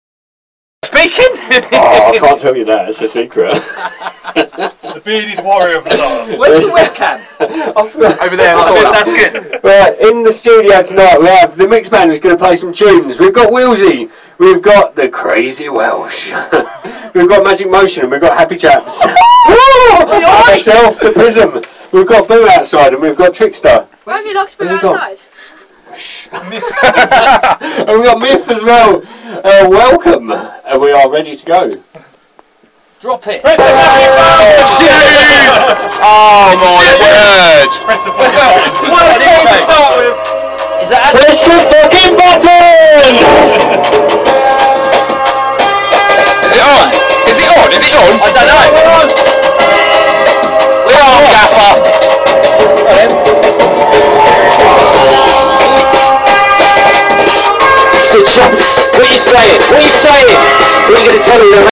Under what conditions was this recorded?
LIVE all around the world